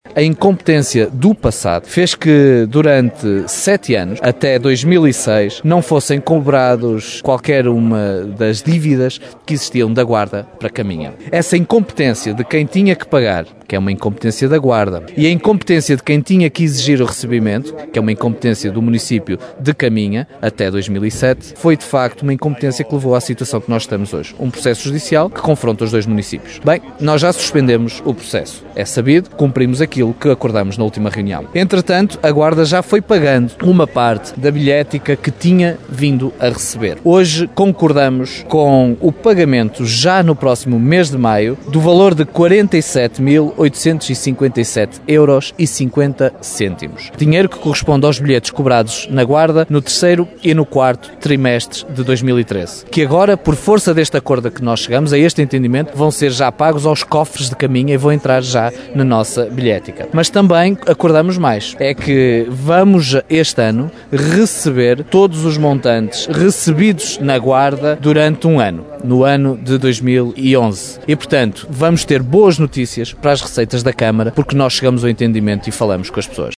É o que diz agora o autarca de Caminha, Miguel Alves, tal como já defendia o anterior executivo camarário liderado pelo PSD e por Júlia Paula Costa, que pôs o caso em tribunal e que o socialista Miguel Alves acabaria por retirar com fortes críticas à decisão dos anteriores autarcas.